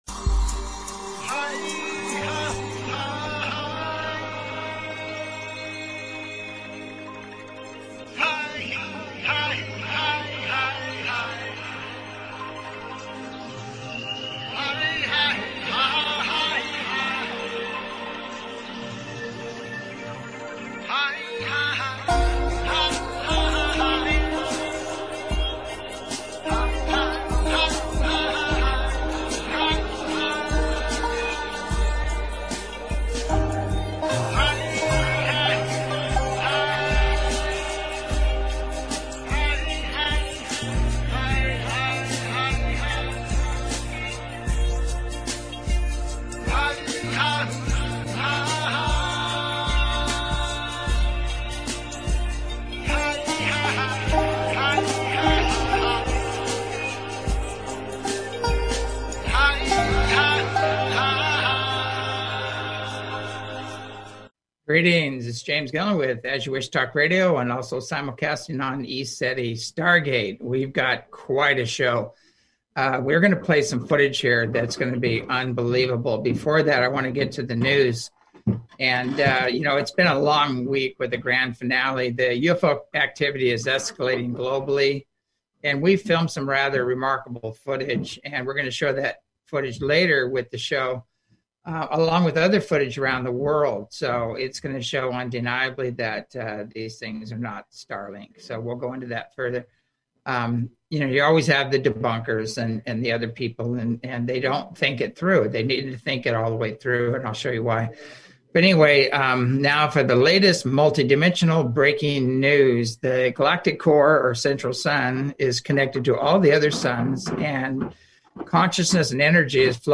Talk Show Episode, Audio Podcast, As You Wish Talk Radio and Multidimensional Update and the Days to Come on , show guests , about Multidimensional Update,Days to Come, categorized as Earth & Space,News,Paranormal,Physics & Metaphysics,Politics & Government,Self Help,Society and Culture,Spiritual,Theory & Conspiracy
As you Wish Talk Radio, cutting edge authors, healers & scientists broadcasted Live from the ECETI ranch, an internationally known UFO & Paranormal hot spot.